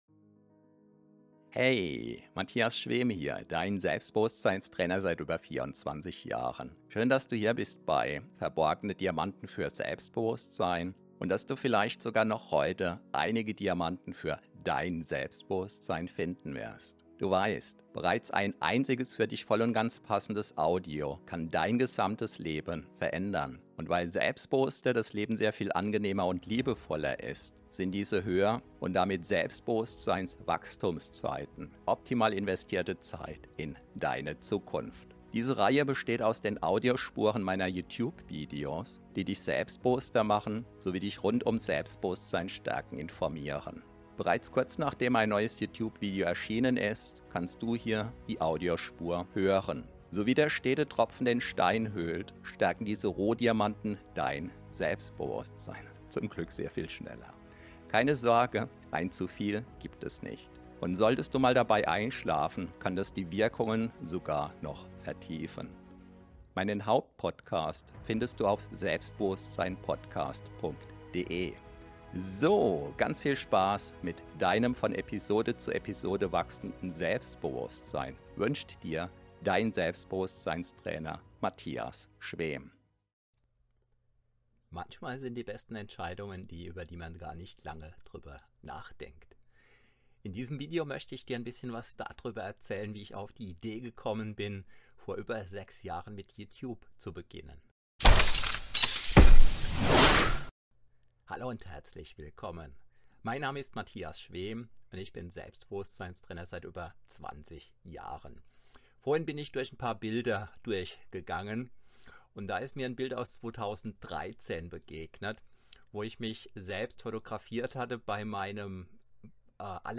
========================= Musik Cell_Phone_Vibrate.mp3 (Abgerufen